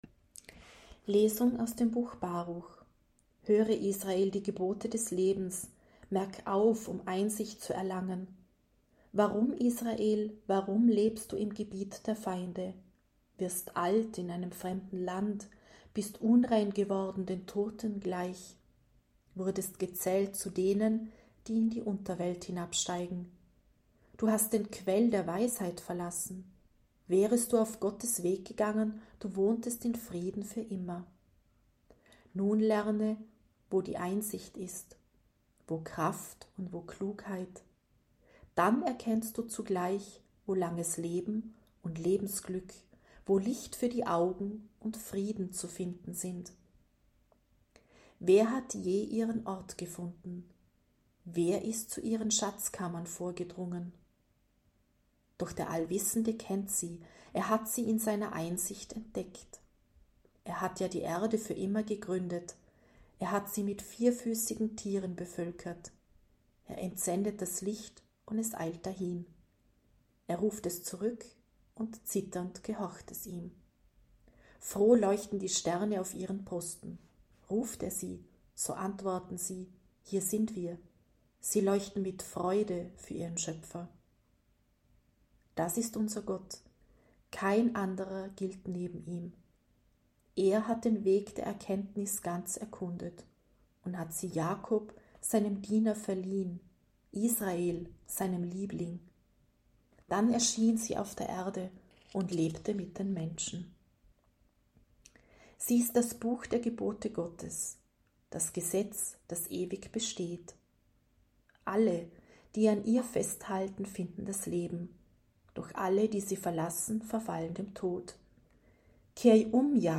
C-Osternacht-6.-Lesung.mp3